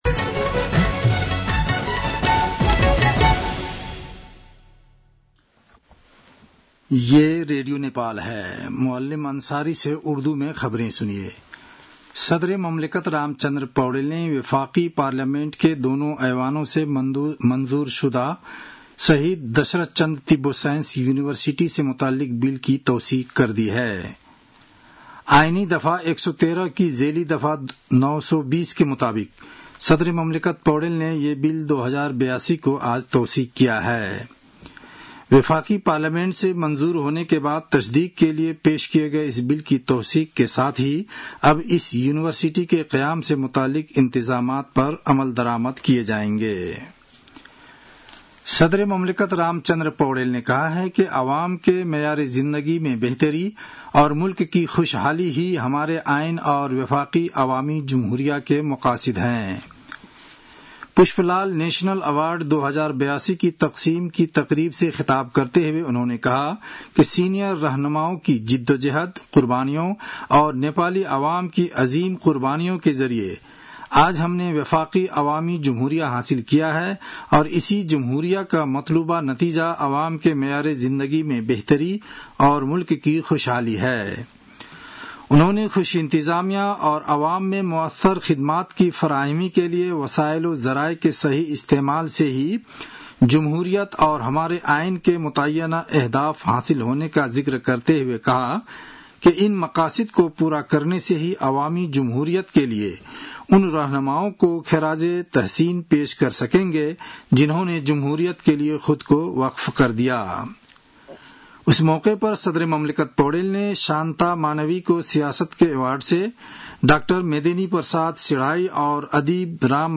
उर्दु भाषामा समाचार : ४ असार , २०८२